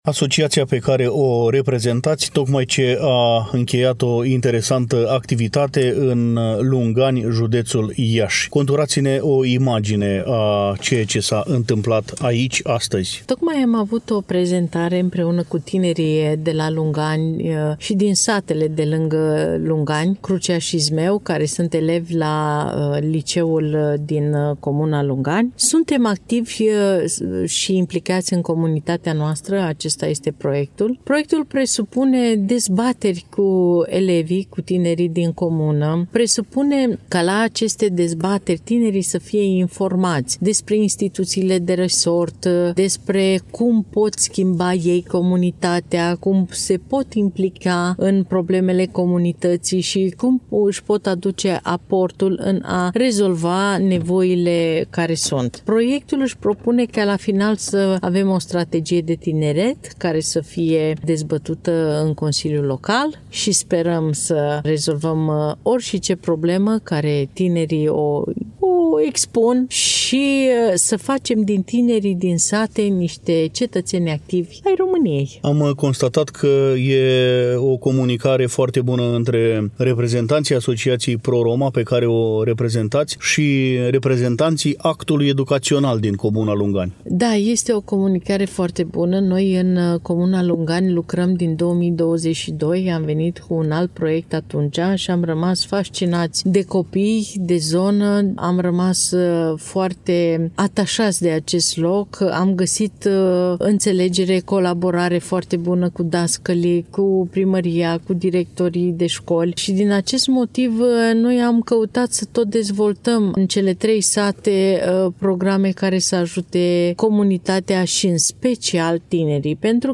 Am făcut popas în incinta Școlii Profesionale din Lungani, acolo unde reprezentanții au prezentat tinerilor romi din comună proiectul Suntem activi și împlicați în comunitatea noastră.